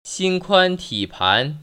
[xīn kuān tĭ pán] 신콴티판  ▶